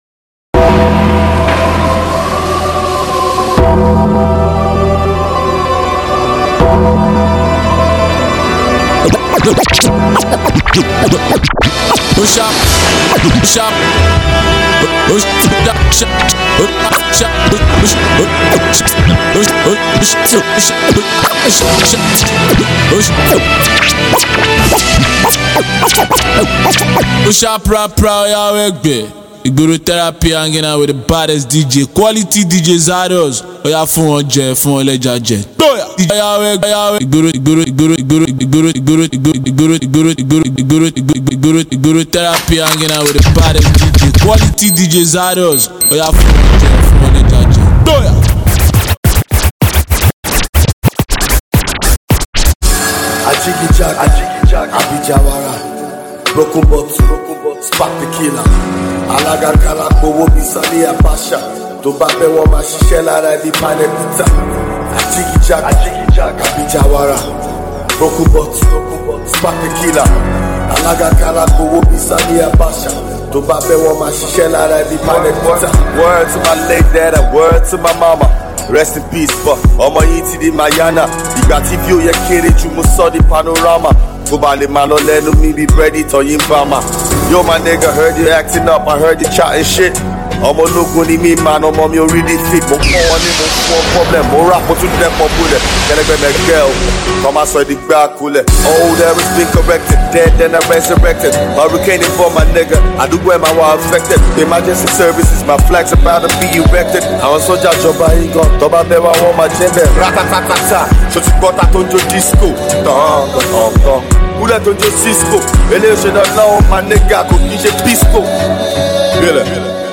this mixtape will keep you dancing for the whole of the mix